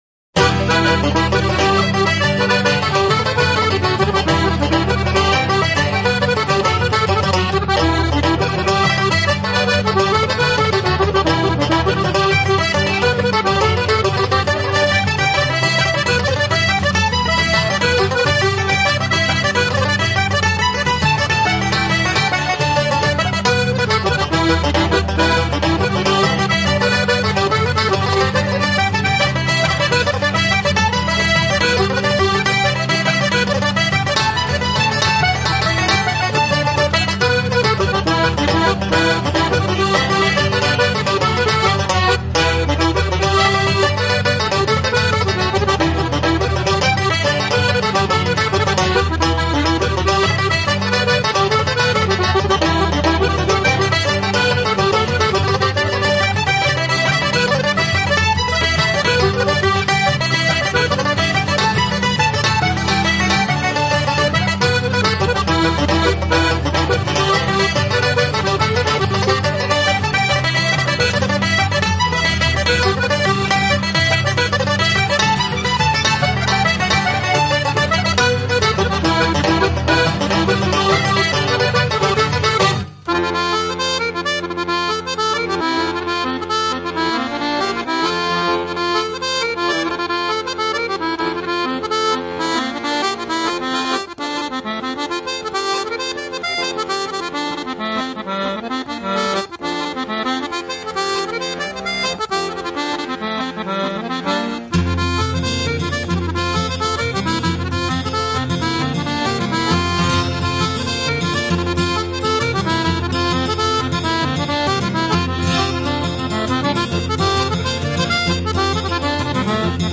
【凯尔特】